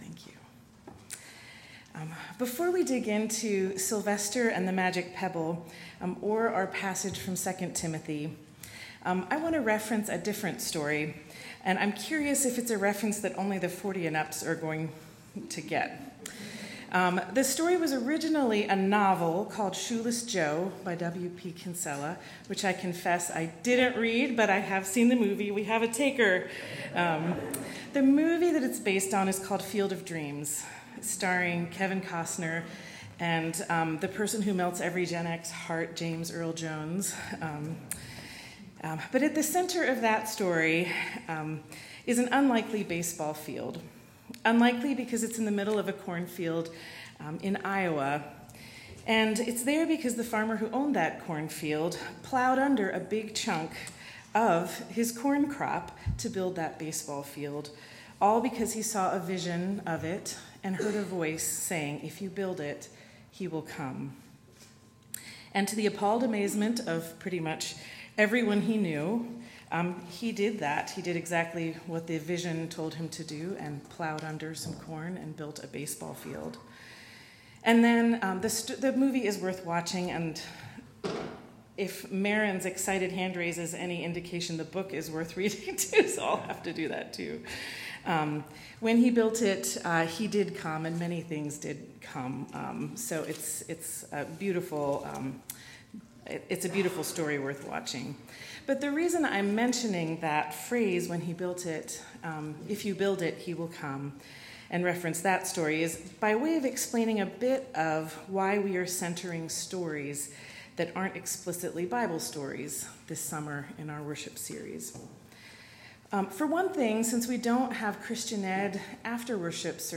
72824-sermon-.m4a